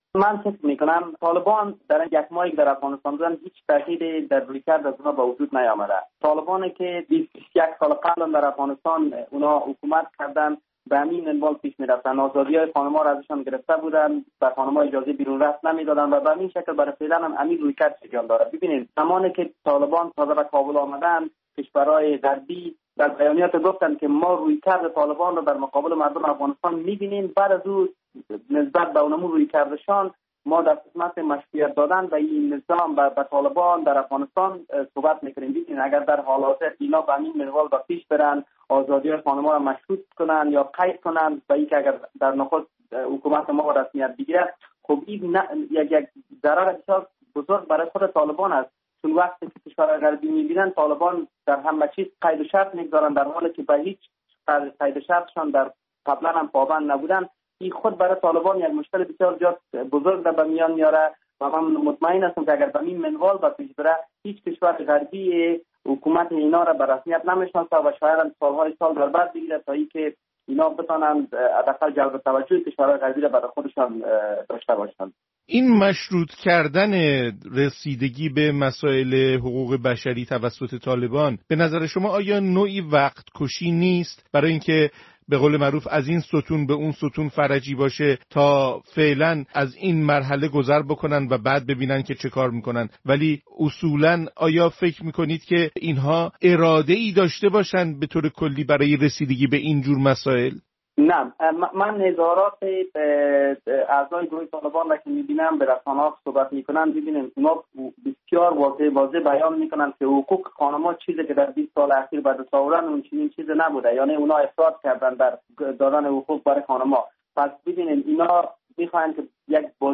کارشناس و تحلیلگر مسائل افغانستان